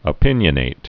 (ə-pĭnyə-năt)